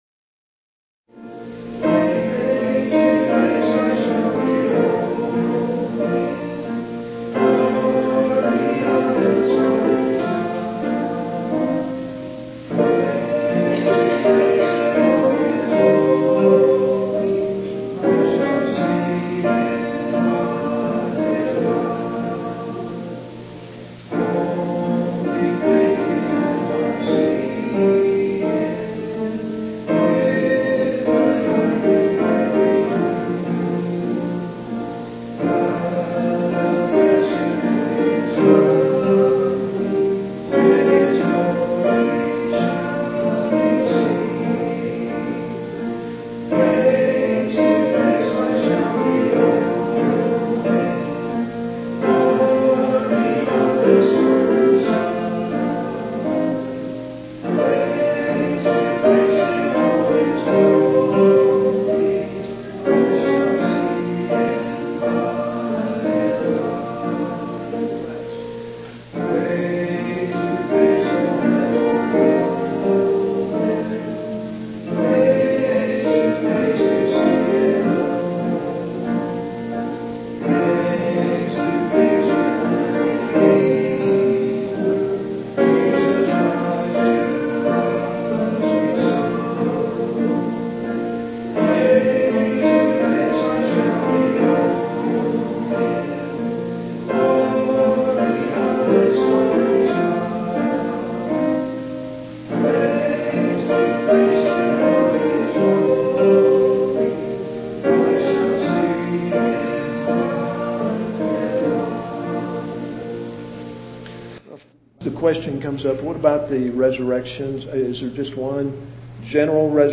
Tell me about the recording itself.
PLAY: Revelation 20: The Millenium, PM Service, May 16, 2010 Scripture: Revelation 20.